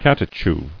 [cat·e·chu]